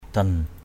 /d̪ʌn/ (d.) ác thần = génie malfaisant. brei dan b] dN cúng cho ác thần = faire des offrandes au génie malfaisant.